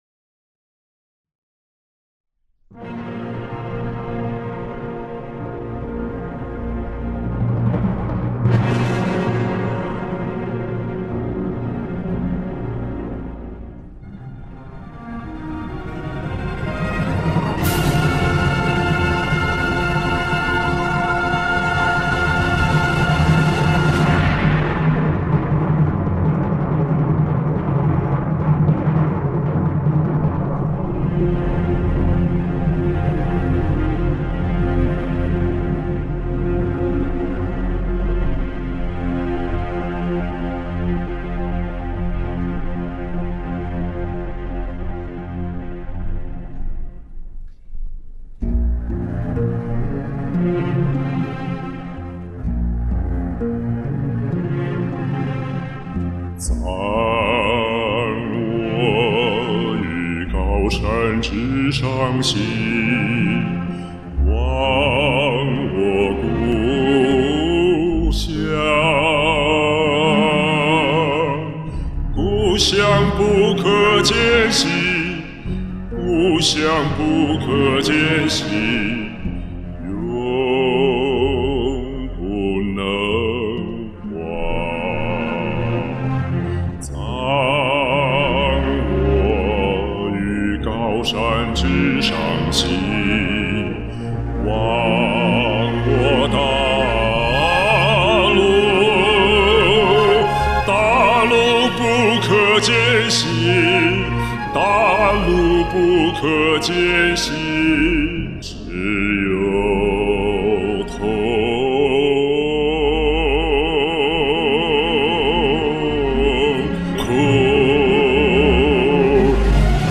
这是一首非常典型的优秀的少有的男中音艺术歌曲。
这次唱升一个key。
超级有魅力的男中音！